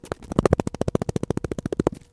描述： 拉紧遮蔽胶带条（拉伸操纵）